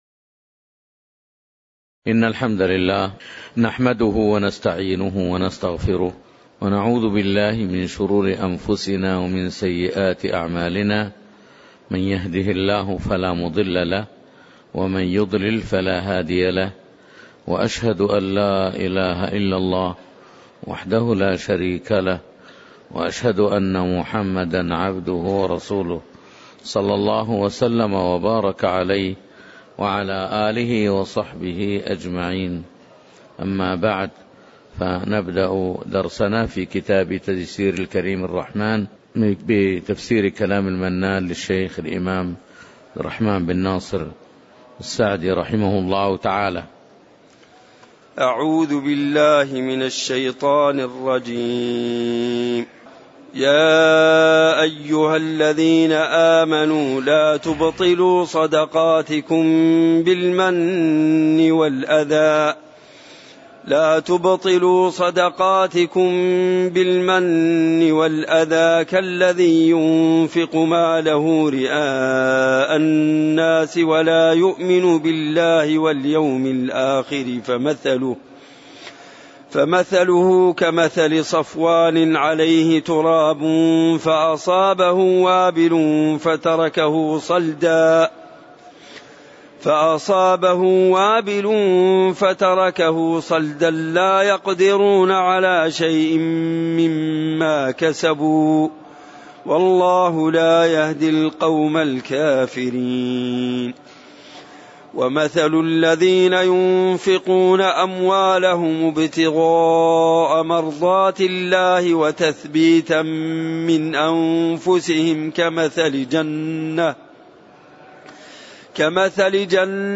تاريخ النشر ١٥ جمادى الآخرة ١٤٣٩ هـ المكان: المسجد النبوي الشيخ